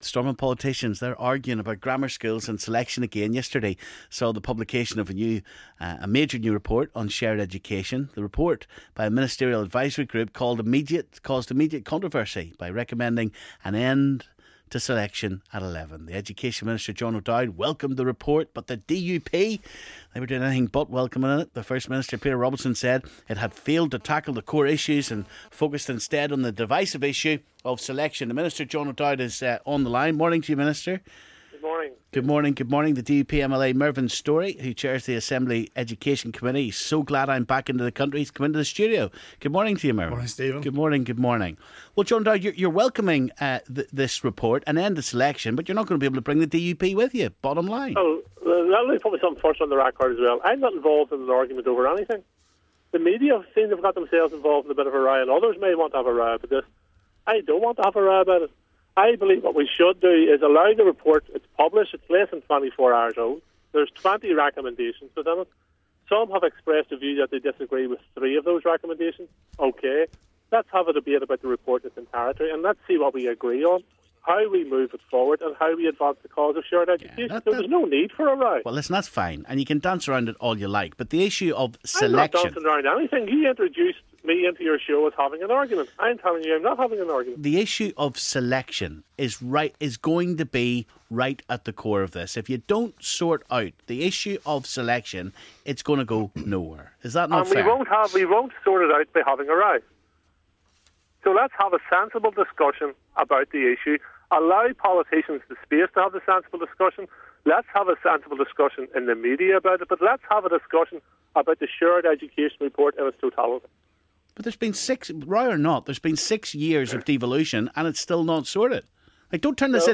A new report on shared education recommends ending selection at 11. Mervyn Storey & John O'Dowd discuss.